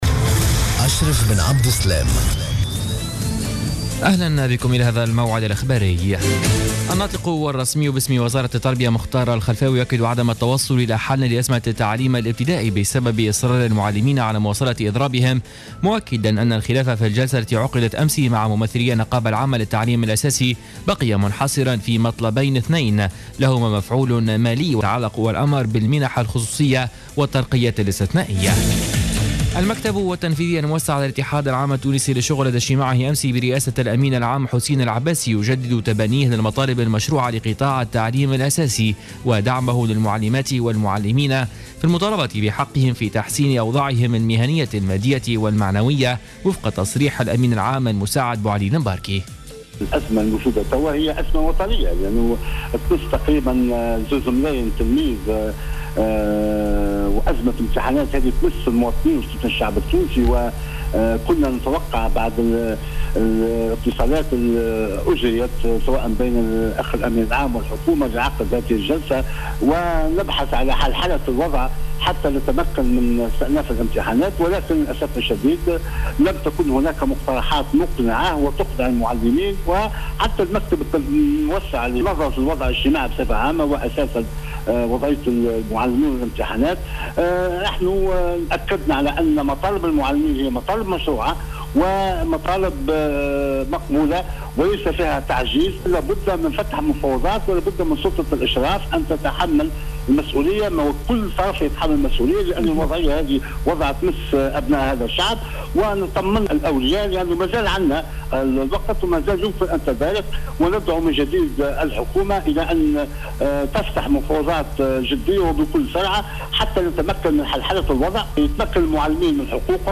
نشرة أخبار منتصف الليل ليوم الثلاثاء 09 جوان 2015